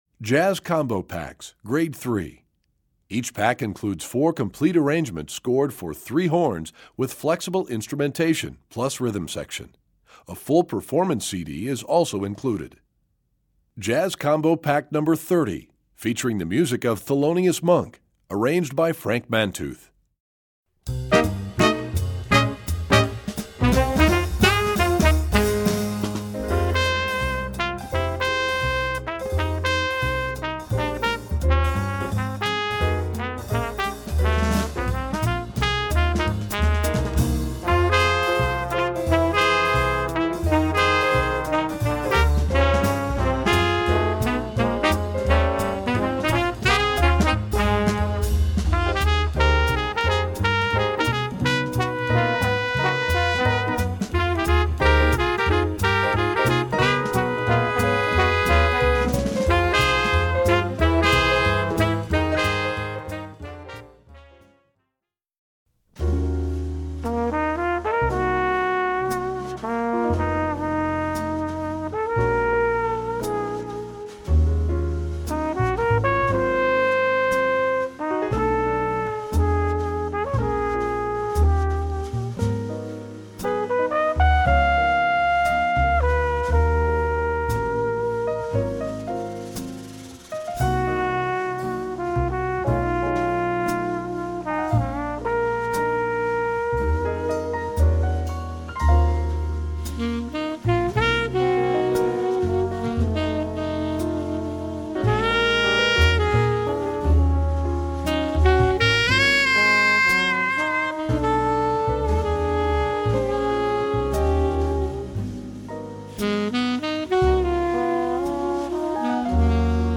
Voicing: Combo w/ Audio